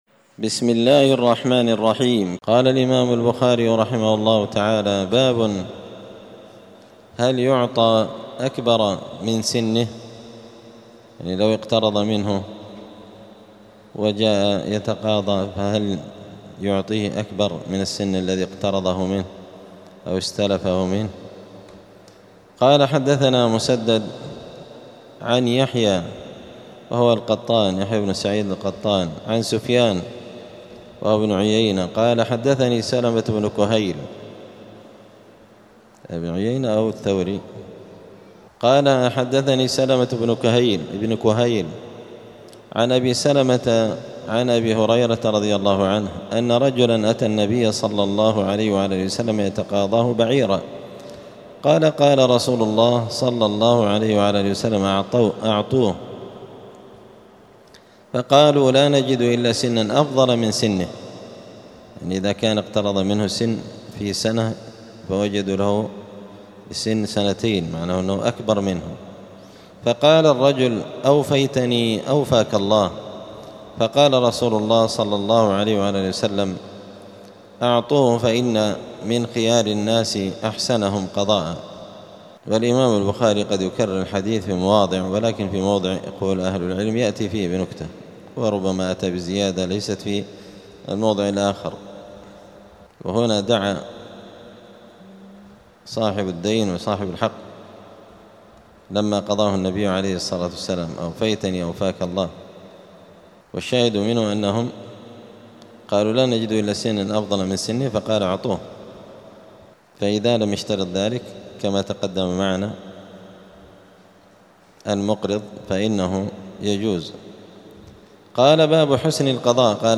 دار الحديث السلفية بمسجد الفرقان قشن المهرة اليمن